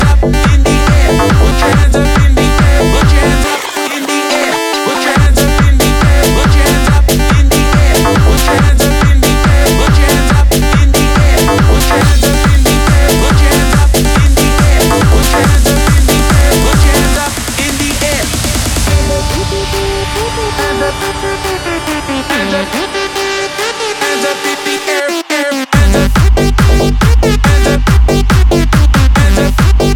2024-05-03 Жанр: Танцевальные Длительность